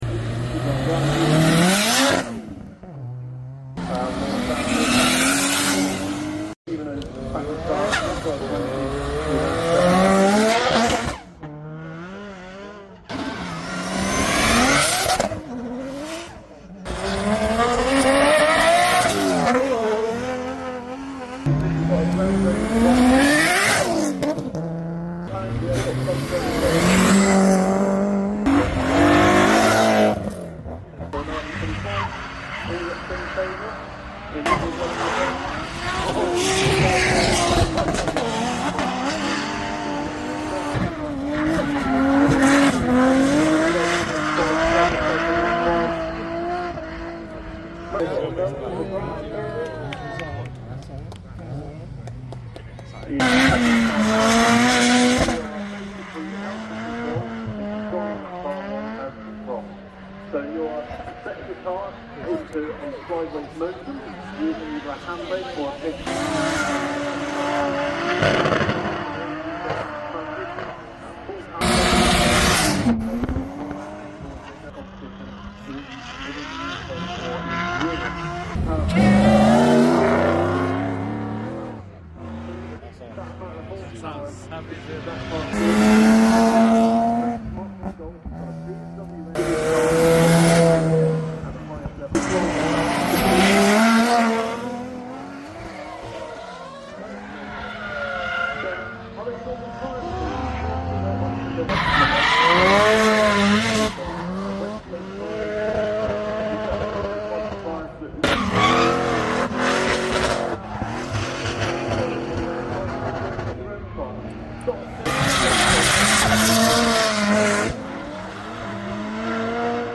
DRIFTING ACTION AT CASTLE COMBE